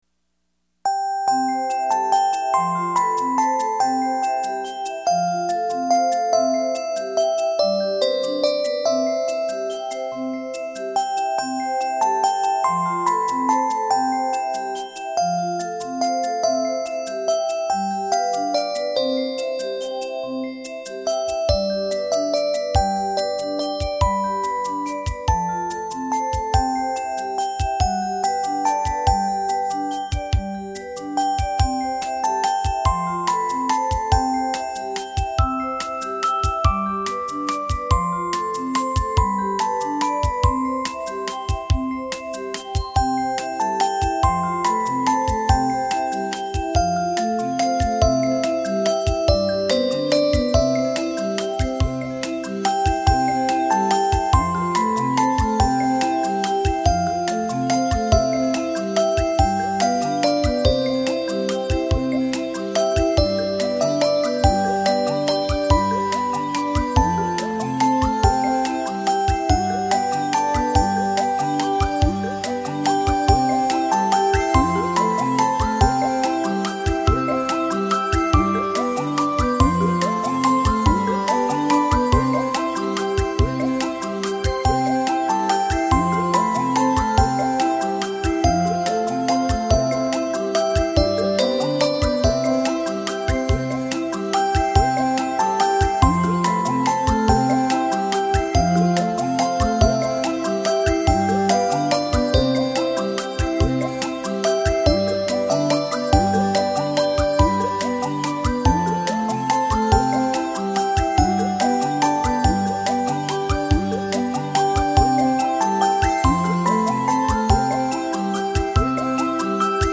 幽秘的旋律轻轻飘落床前，如梦如幻的音符掠过心湖，宁静夜晚音乐是梦的天堂。